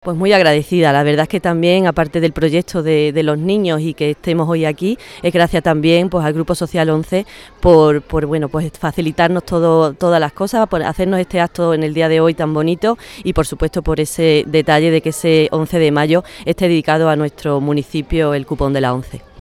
La alcaldesa de Fuente Obejuna, Silvia Mellado, y el presidente del Grupo Social ONCE, Miguel Carballeda, participaron junto al director general de Personas con Discapacidad de la Junta de Andalucía, Pedro Calbó, en el descubrimiento de la placa de la nueva calle en un acto festivo al que asistieron cientos de estudiantes de la localidad cordobesa que disfrutaron de una alegre jornada en la que no faltaron los globos y las charangas.